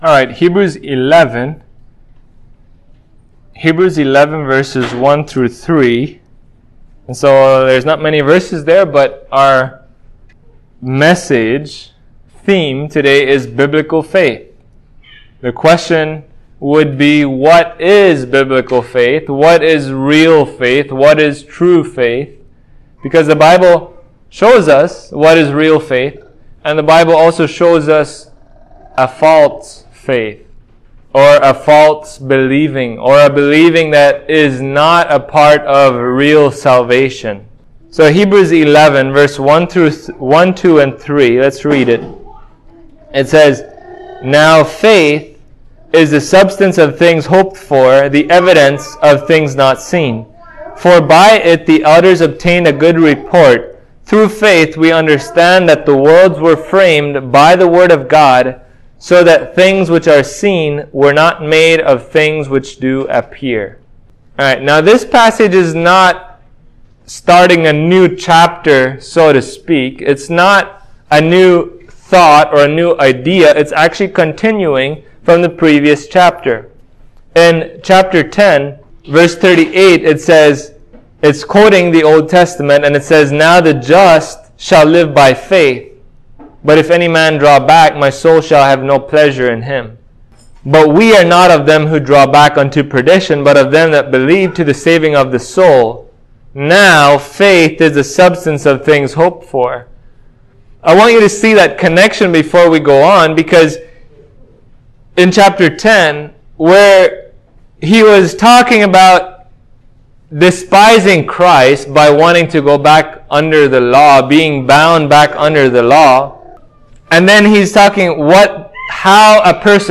Hebrews 11:1-3 Service Type: Sunday Morning What is true faith?